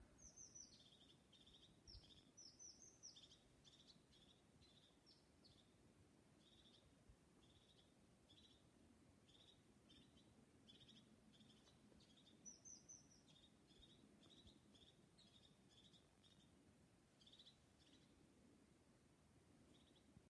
Cekulzīlīte, Lophophanes cristatus
StatussDzirdēta balss, saucieni
PiezīmesVai tā varētu būt dzilnīša balss?